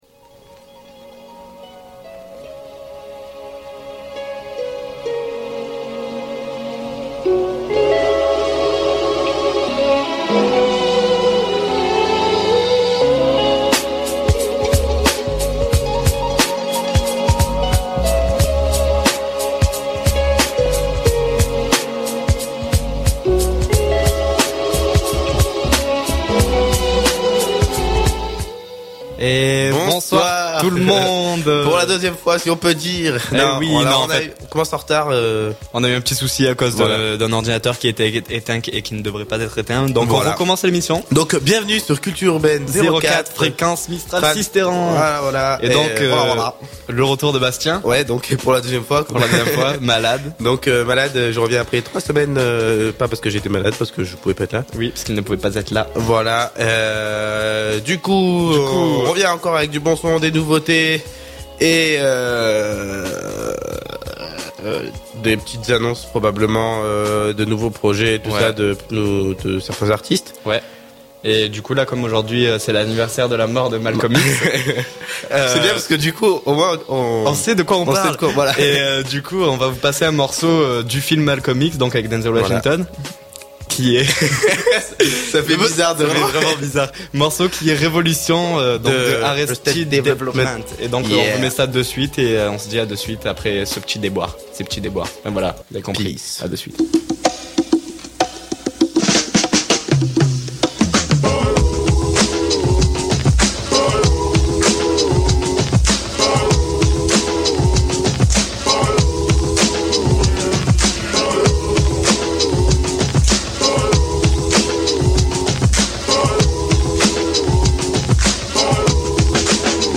Ces jeunes bas-alpins se mobilisent à l’antenne pour faire découvrir de chanteurs émergeants, mais aussi afin de redécouvrir quelques titres impérissables. Invités, Freestyle, concerts à venir…